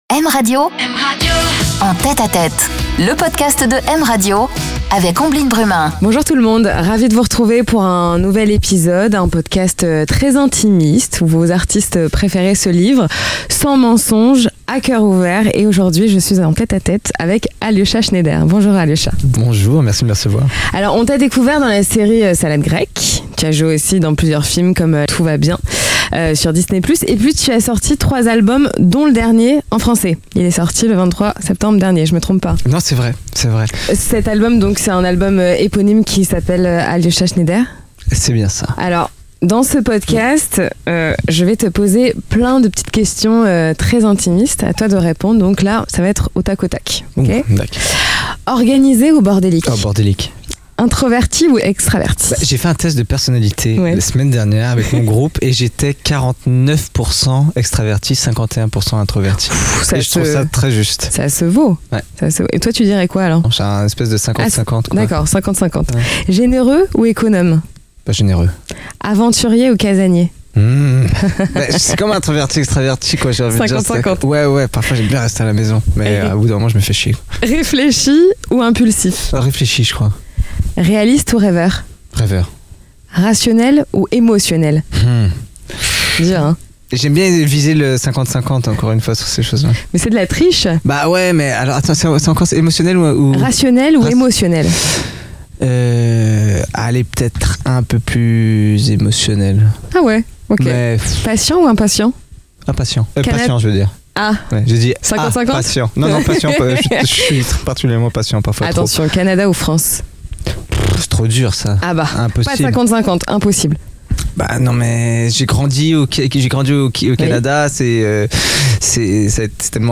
Une interview en confidence, dans l'intimité des artistes